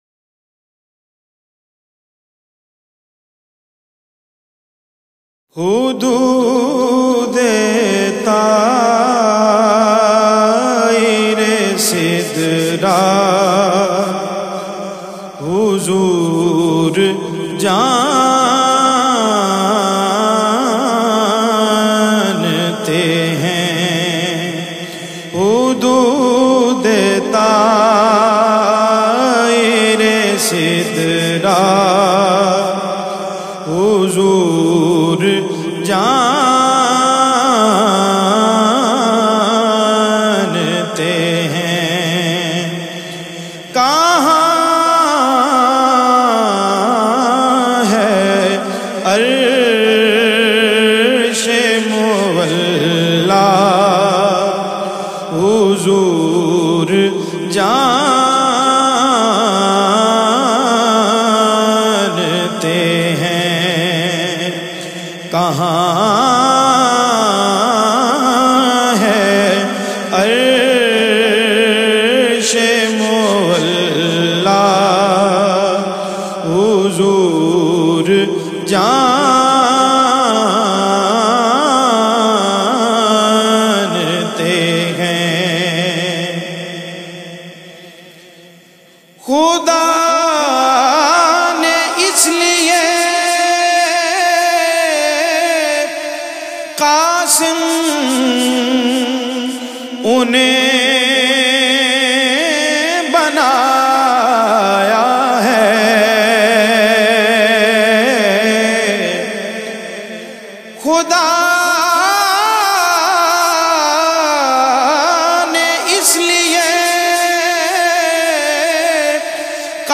has very sweet and magical voice with wonderful control